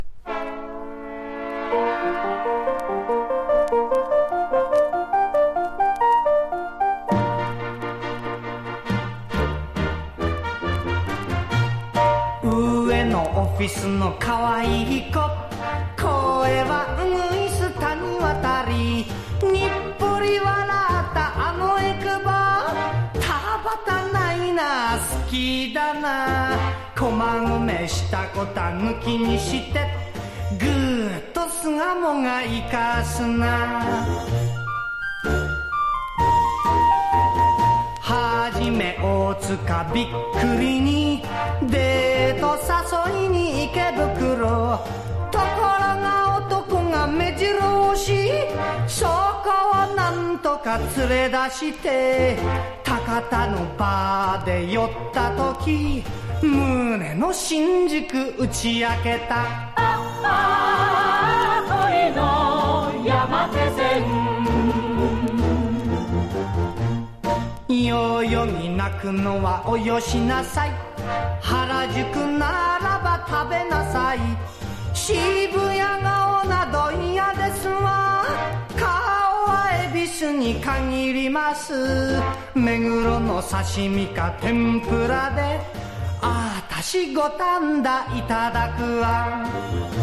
鋭いホーンの